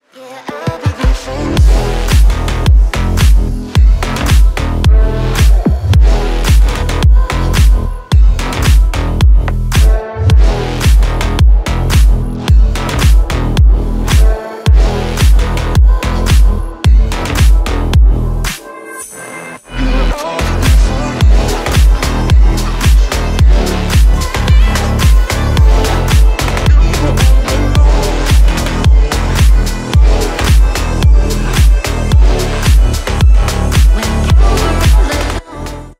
• Качество: 320 kbps, Stereo
Танцевальные
клубные
громкие